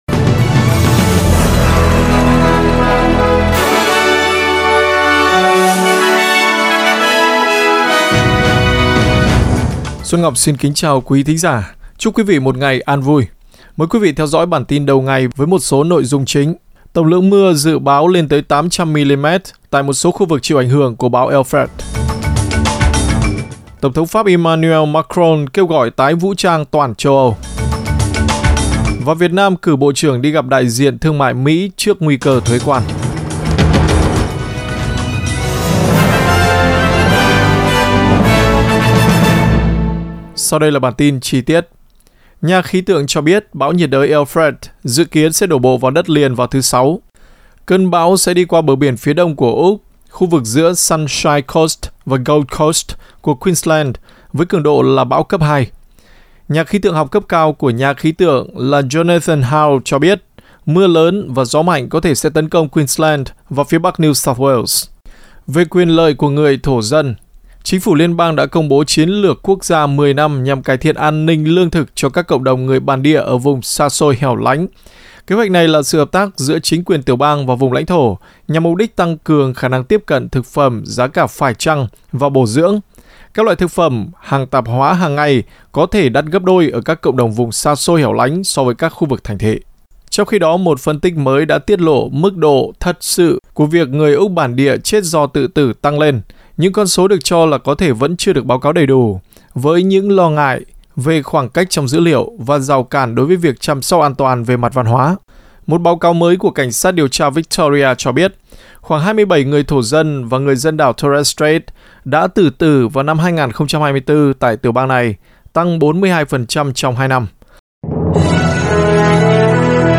Vietnamese news bulletin Source: Getty